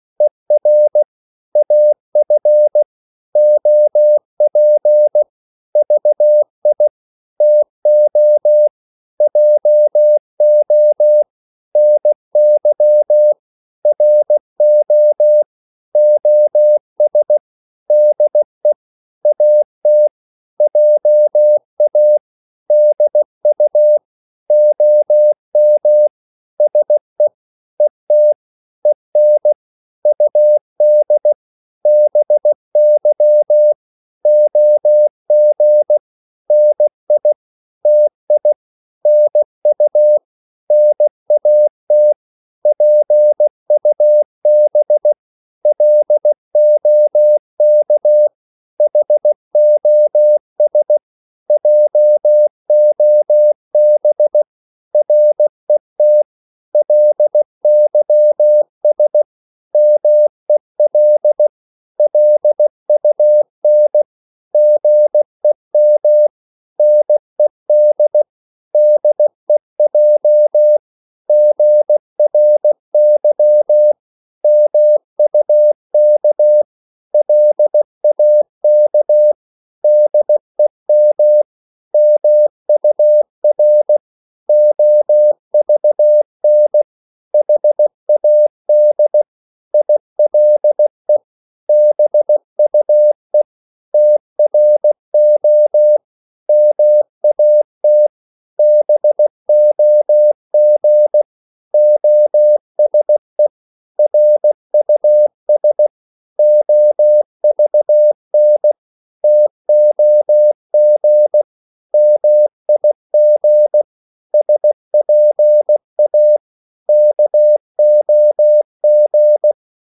15 - 19 wpm | CW med Gnister
Hastighed: 15 - 19 wpm
Korte ord DK 16wpm.mp3